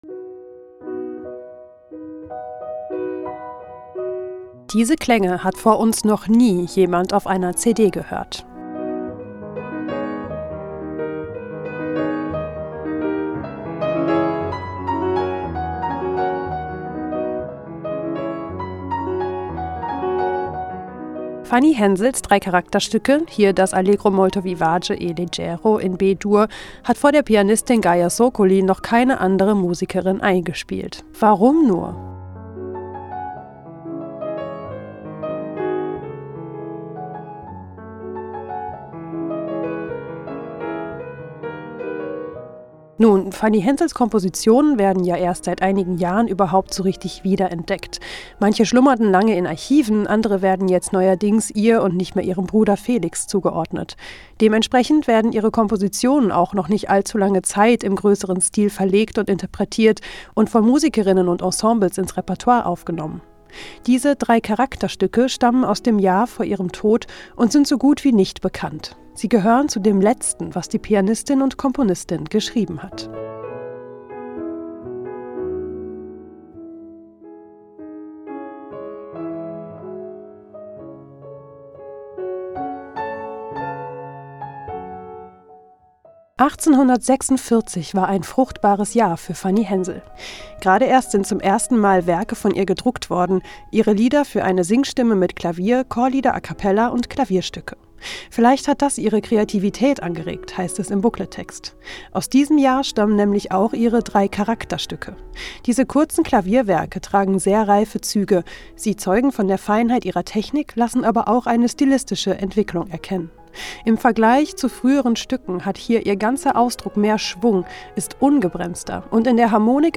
Klaviersonaten